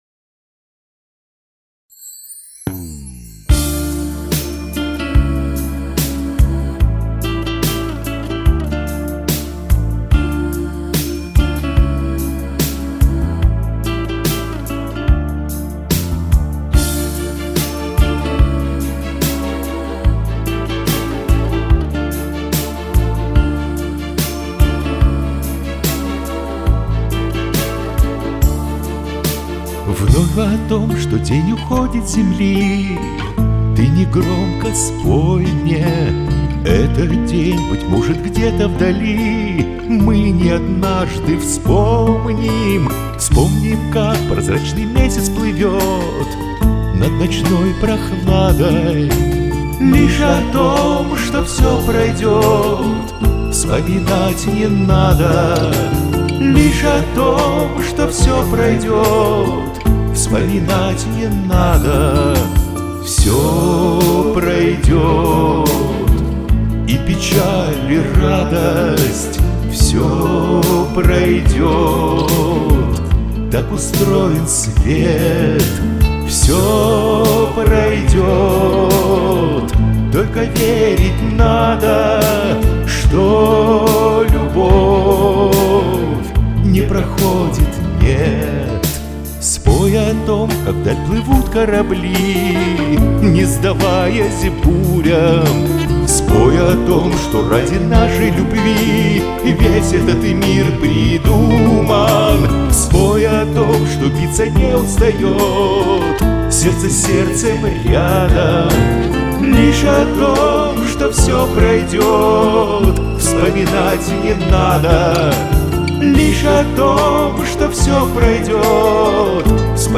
Вы исполнили по-мужски, сдержанокрасиво!